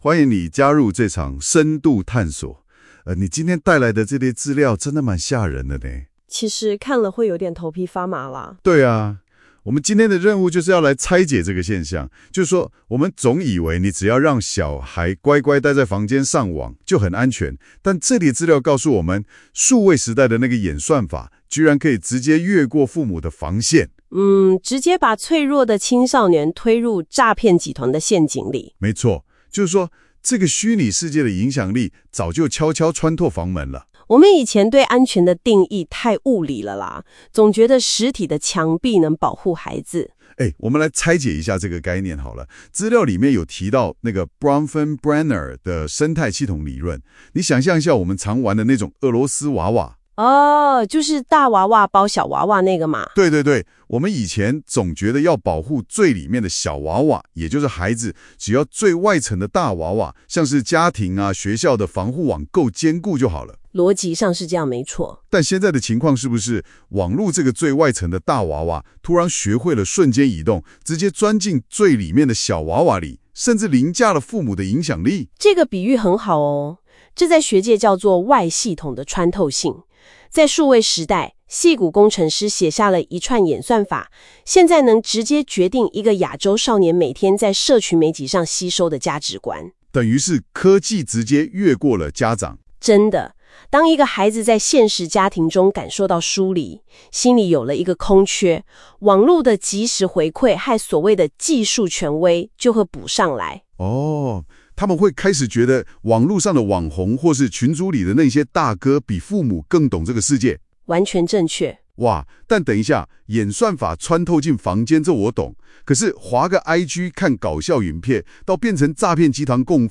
本段錄音為本章內容的精華摘要，由 AI 生成，協助您快速掌握數位時代下的生態系統變遷。